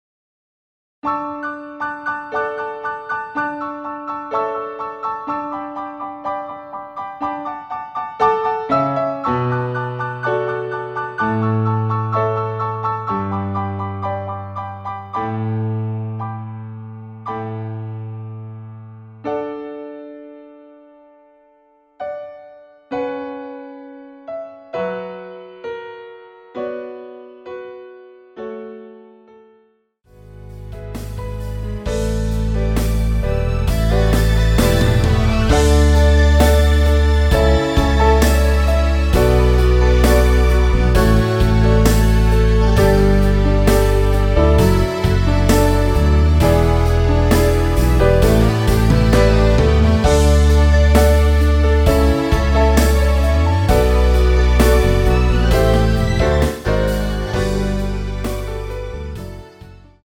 원키에서(-4)내린 MR입니다.
앞부분30초, 뒷부분30초씩 편집해서 올려 드리고 있습니다.
중간에 음이 끈어지고 다시 나오는 이유는